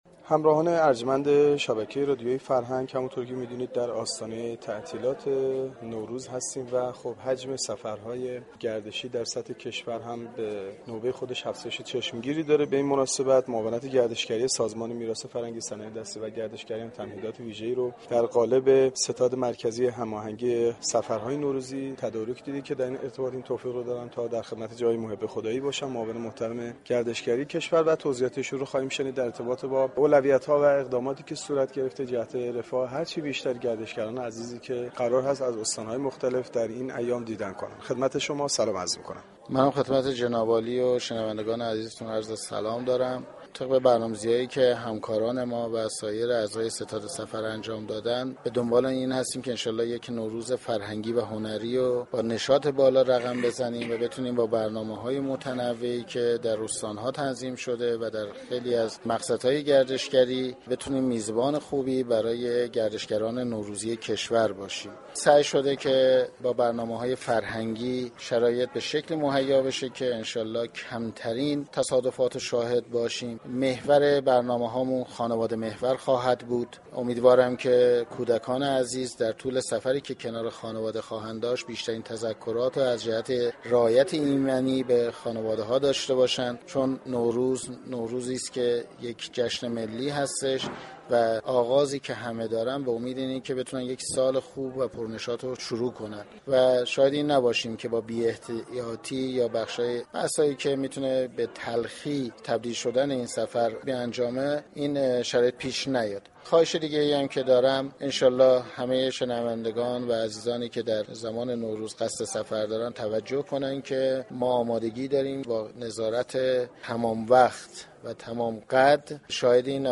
محمد محب خدایی معاون گردشگری سازمان در گفتگوی اختصاصی با گزارشگر رادیو فرهنگ درباره ی اقدامات در دست انجام گفت : طبق برنامه ریزی های انجام شده هدف ما برگزاری نوروزی فرهنگی و هنری در سراسر كشور است و به همین منظور برنامه های فرهنگی – هنری مختلفی را در مناطق مختلف تدارك دیدیم تا بتوانیم میزبان خوبی برای گرشگران نوروزی در سراسر كشور باشیم .